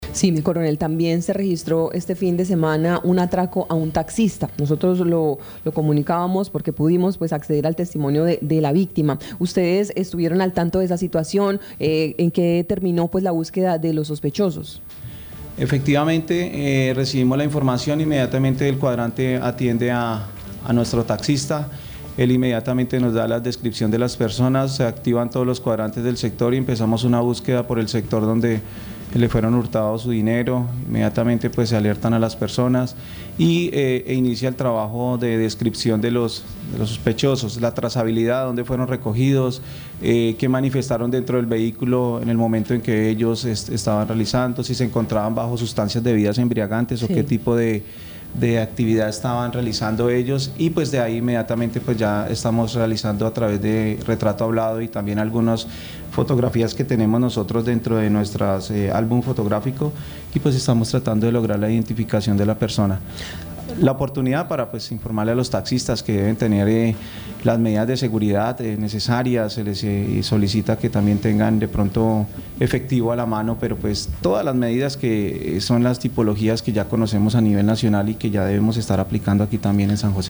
Escuche a coronel Ángel Alexander Galvis Ballén, comandante Departamento Policía Guaviare.